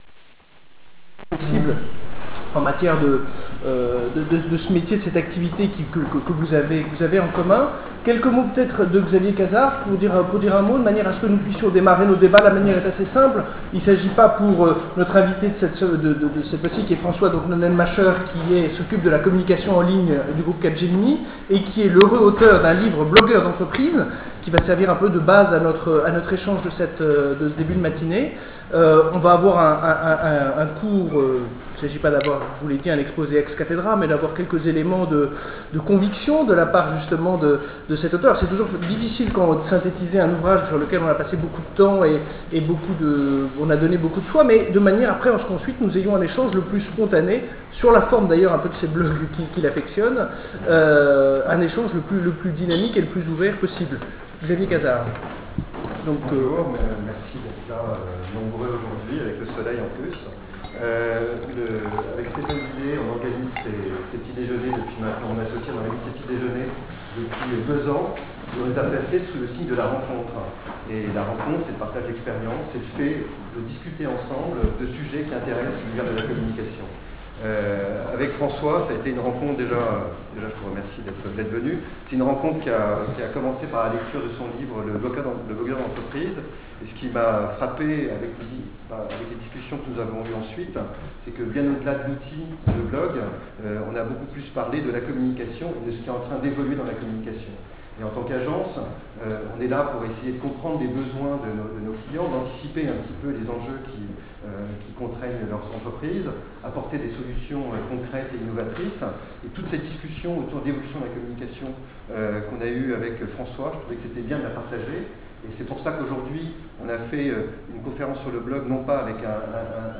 Enregistrement audio du petit-déjeuner débat Entrecom - Press Club du 11 mai 2006 : bienvenue dans la blogosphère
Je vous livre l'enregistrement audio que j'avais capturé avec mon iPod.
Je vais tenter de chapitrer en reprenant par écrit les questions qu'on n'entend pas bien, merci pour votre patience.